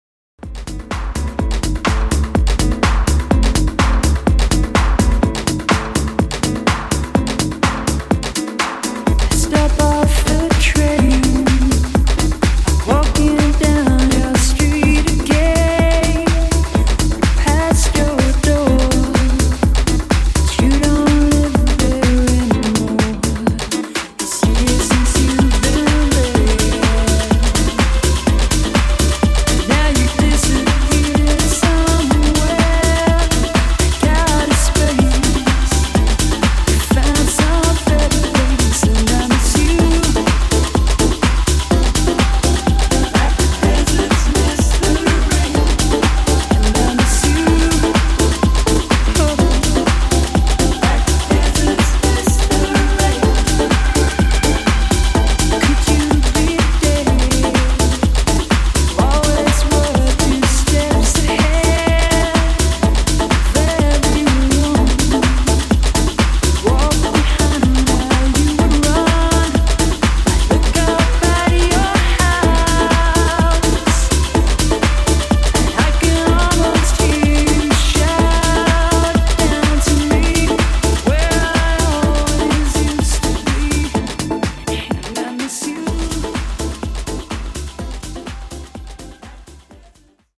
90s Classic House V2)Date Added